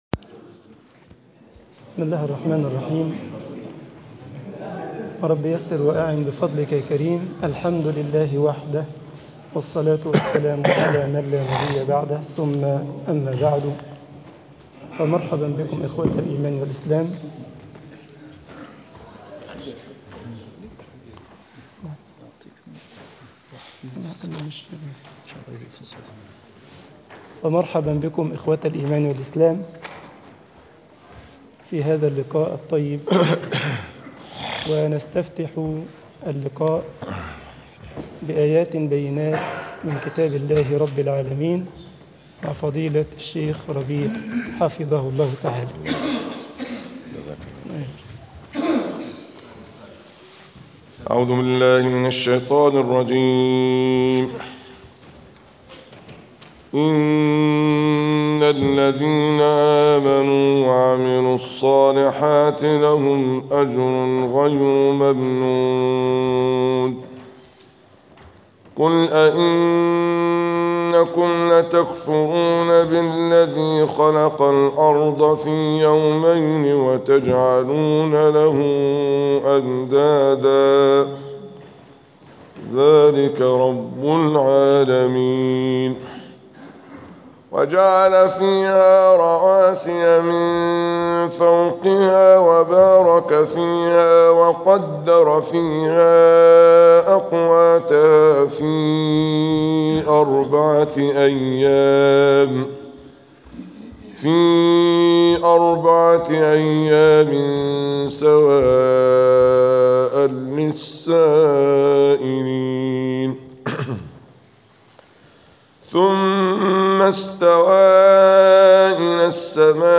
ندوة علمية بمسجد السلام ميونيخ طباعة البريد الإلكتروني التفاصيل كتب بواسطة
Nadwatun ilmiatun bimasjid assalam Munich.mp3